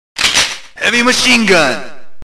PLAY Heavy Machine Gun Metal Slug 1
heavy-machine-gun-metal-slug-1.mp3